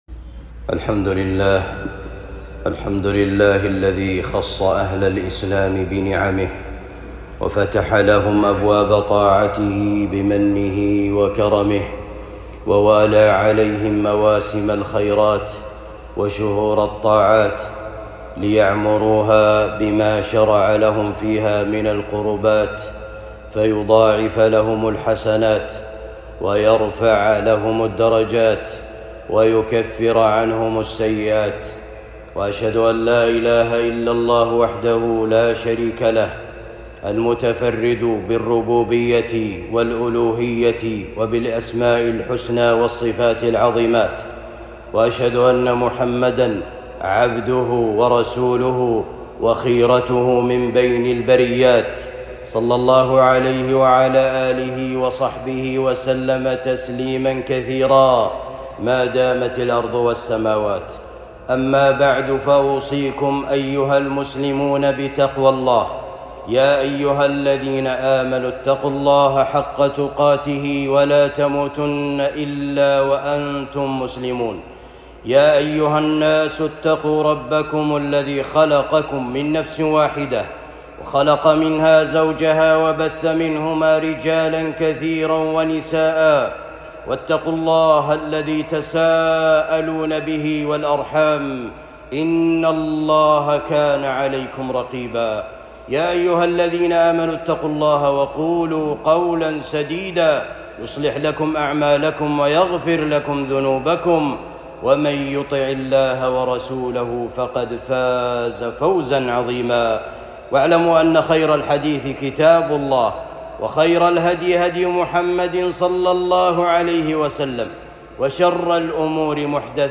خطبة جمعة بعنوان فضائل شهر ذي القعدة